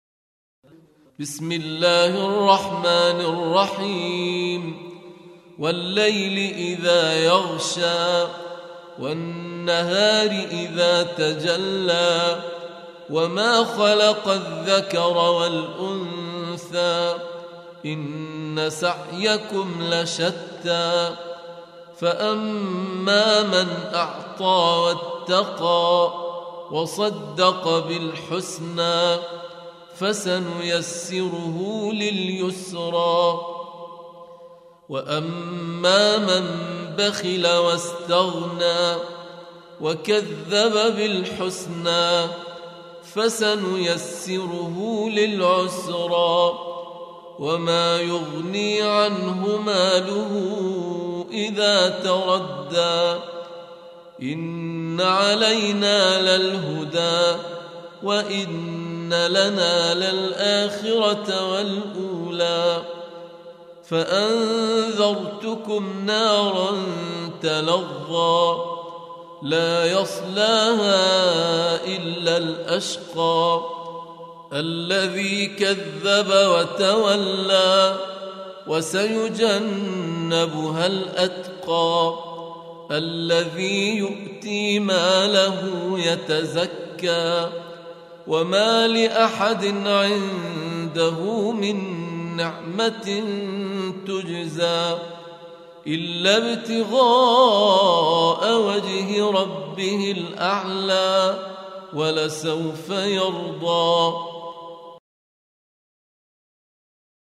Surah Sequence تتابع السورة Download Surah حمّل السورة Reciting Murattalah Audio for 92. Surah Al-Lail سورة الليل N.B *Surah Includes Al-Basmalah Reciters Sequents تتابع التلاوات Reciters Repeats تكرار التلاوات